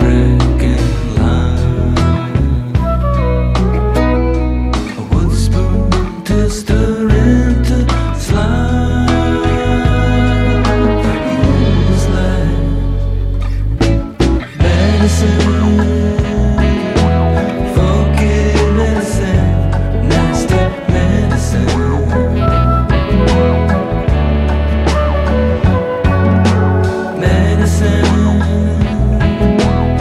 Rock et variétés internationales